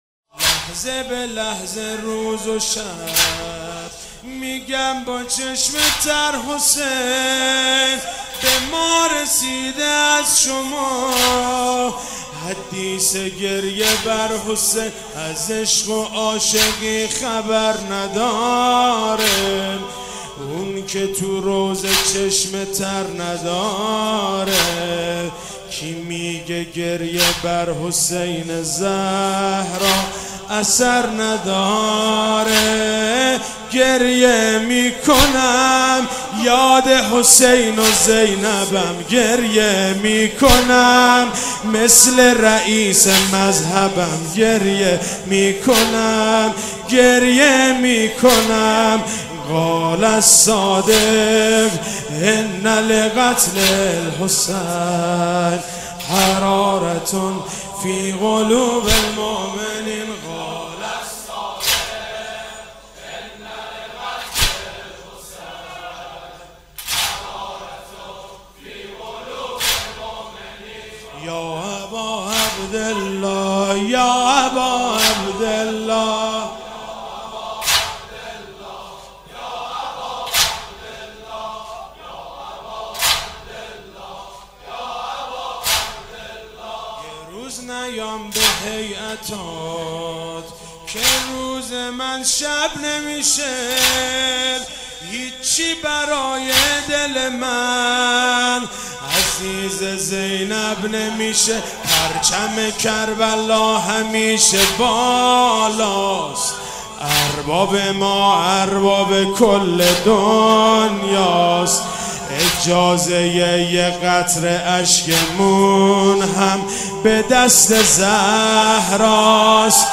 شهادت حضرت امام صادق علیه السلام 1394 | هیات انصارالحجه مشهد
داره بقیع خاک عجیب | زمینه